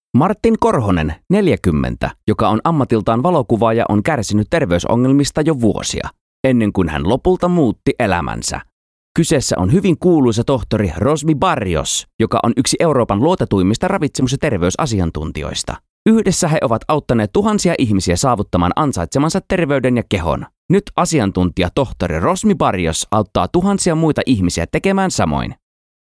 • Finnish Voice Over Sample 2
• Male
• Young Adult